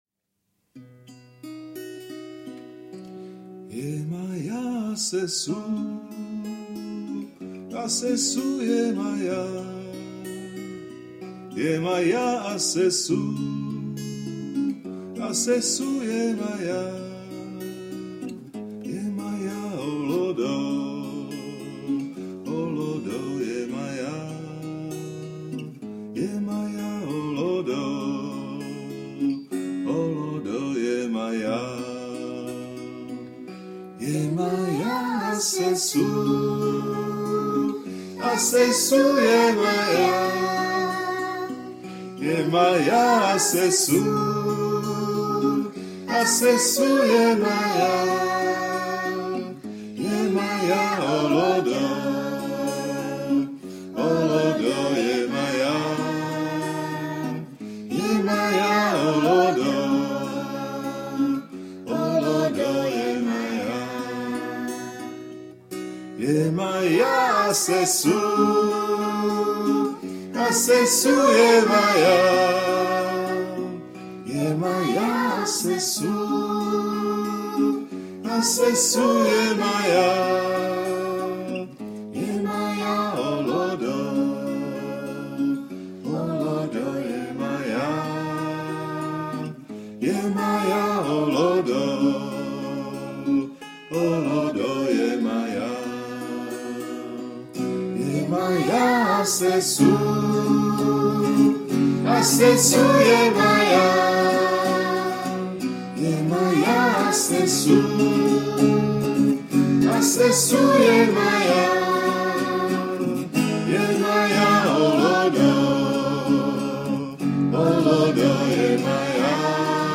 Zpíváme mantry s dětmi
Zpívání manter s dětmi, ukázková nahrávka
Matra s dětmi_Yemaya_assesu.mp3